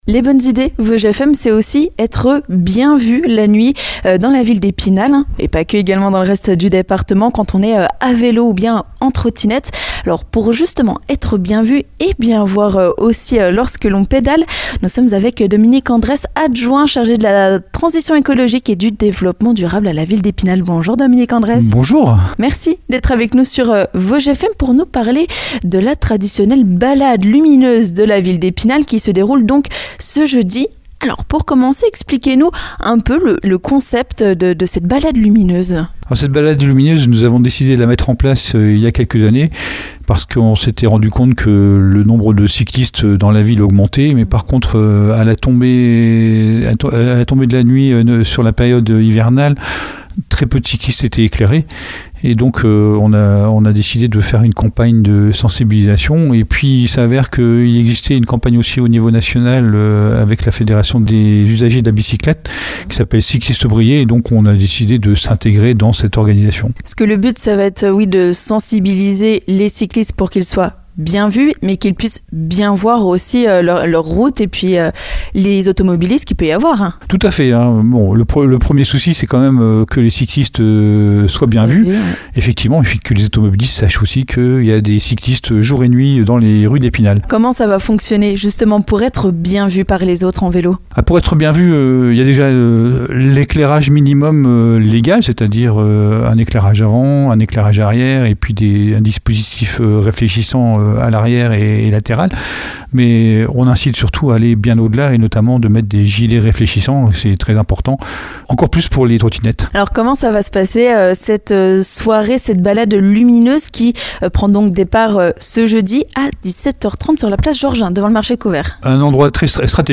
Dans les Bonnes Idées Vosges FM, nous recevons Dominique Andrès, adjoint à la municipalité d'Epinal.